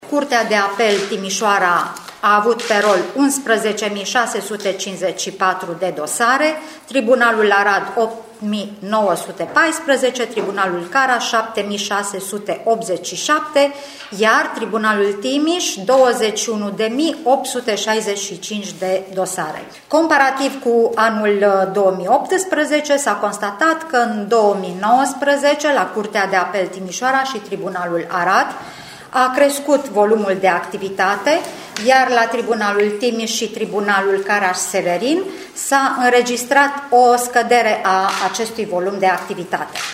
Potrivit președintelui Curții de Apel Timișoara, Erica Nistor, la unele dintre instanțe numărul dosarelor a fost mai mic decât în 2018.
erica-nistor-bilant-2019.mp3